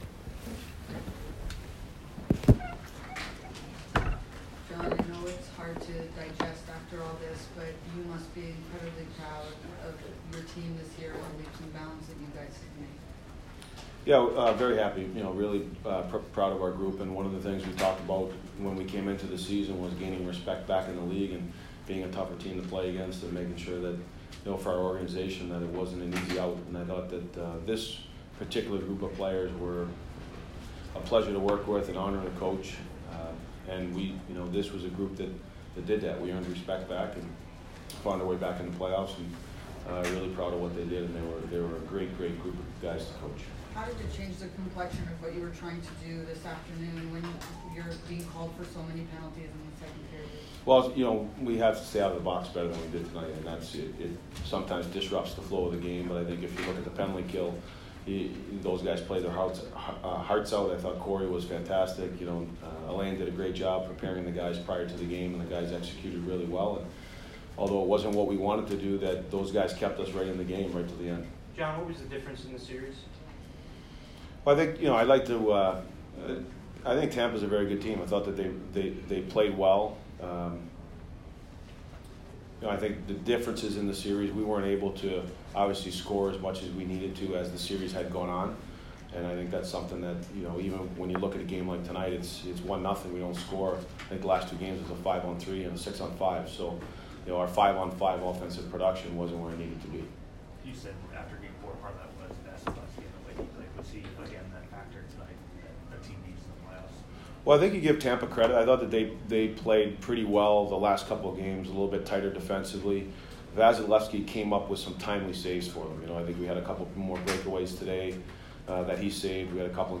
Devils coach John Hynes post-game 4/21